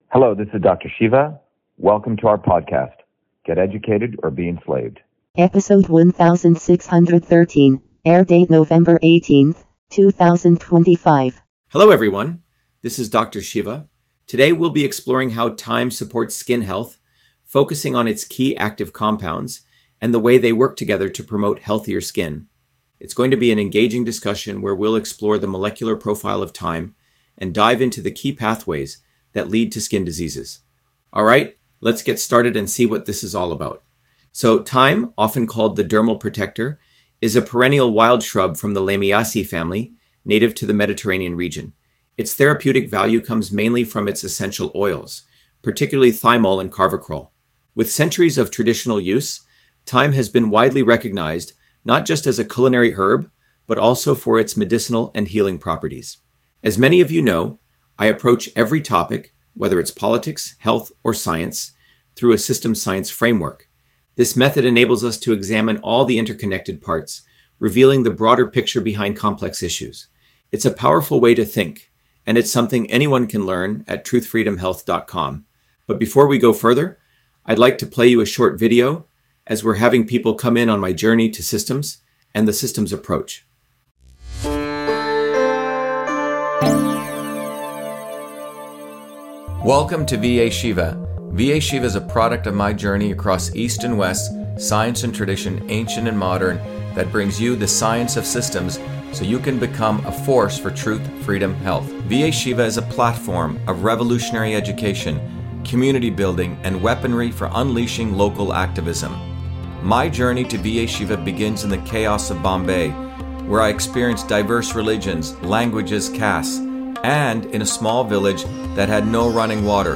In this interview, Dr.SHIVA Ayyadurai, MIT PhD, Inventor of Email, Scientist, Engineer and Candidate for President, Talks about Thyme on Skin Health: A Whole Systems Approach